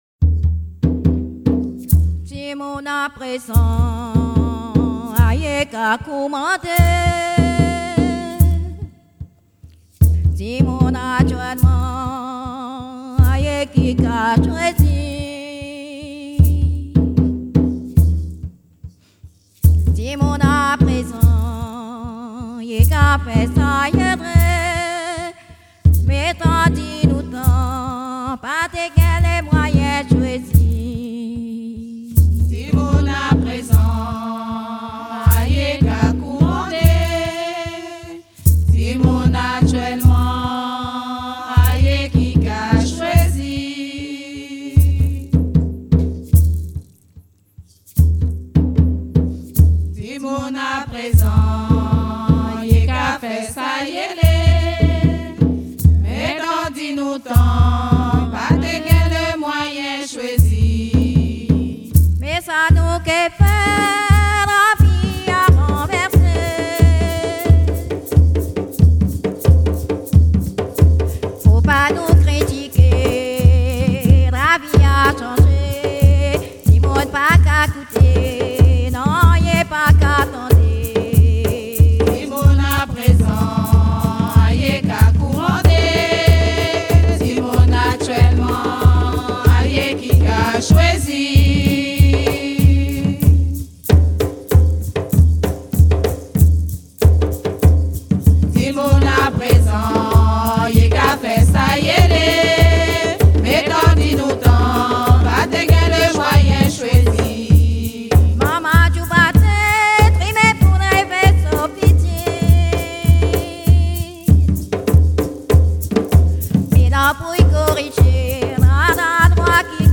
danse : grajé (créole)
Pièce musicale inédite